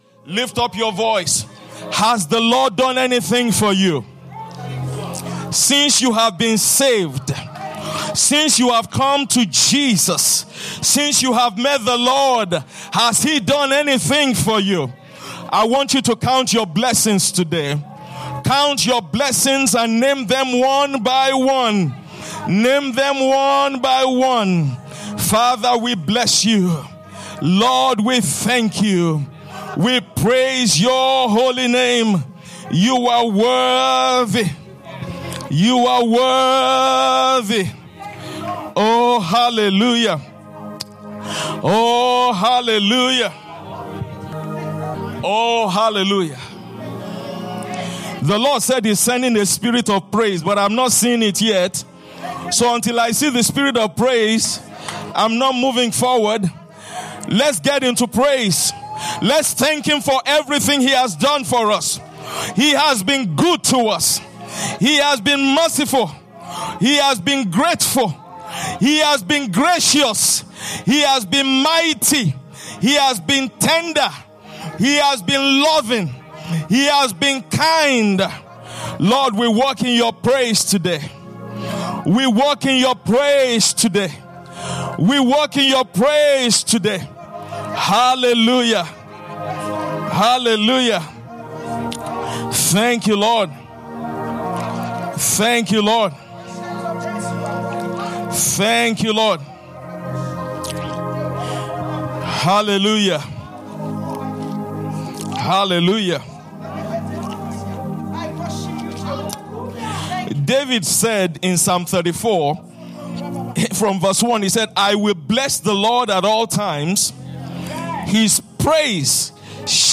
Praise